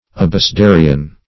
Abecedarian \A`be*ce*da"ri*an\, n. [L. abecedarius.